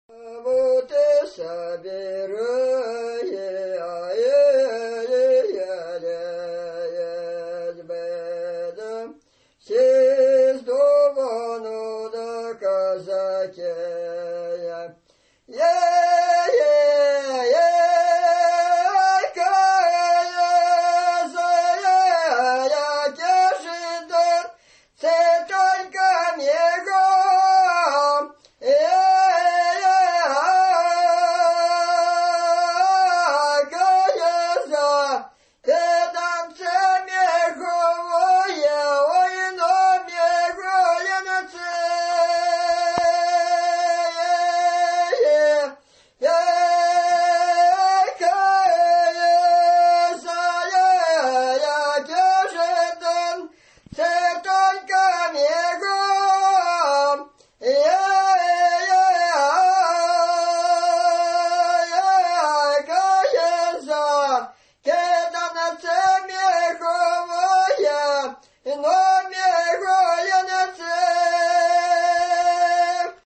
показывает партию дишканта в песне
Место фиксации: Ростовская область, Верхнедонской район, хутор Мрыховский